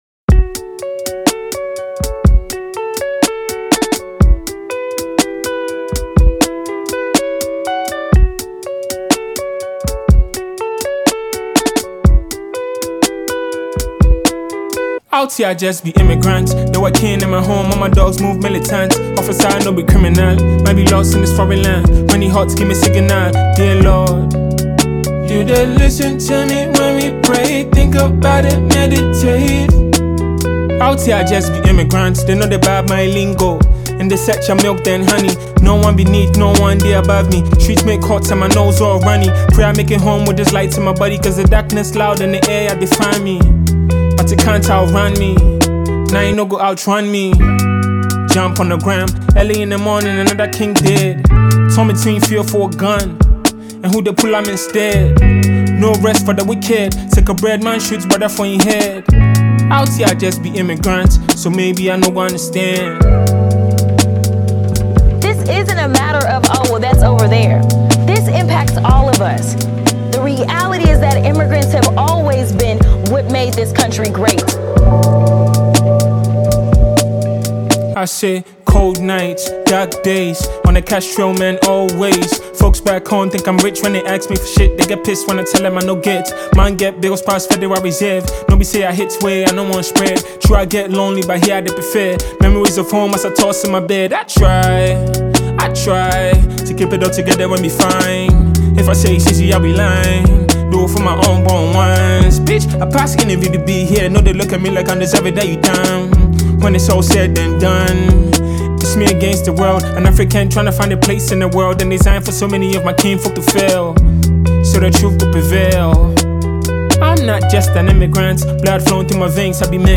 Ghanaian rap
delivers raw, reflective bars
a mellow but hard-hitting instrumental
contemporary hip-hop